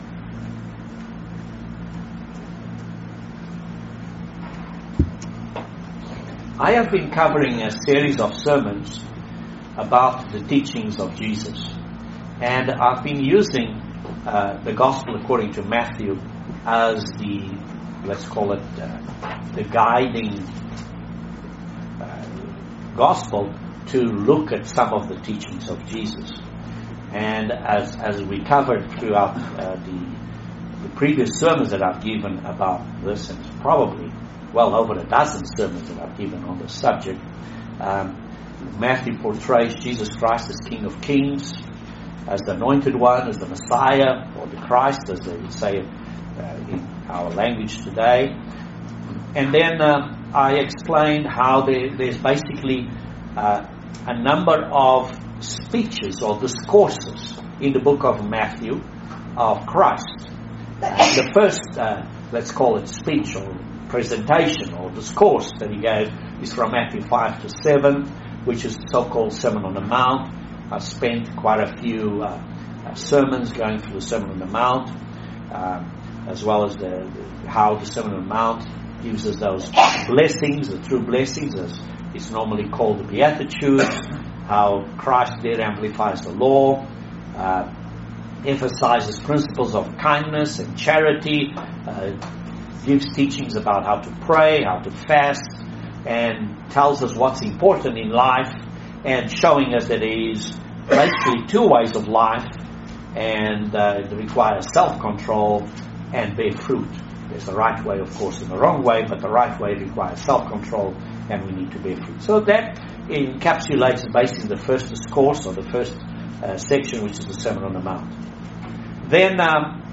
Excellent Bible Study on the book of Matthew chapter 10. Please join us for this interesting study on Matthew 10 and the 12 Apostles.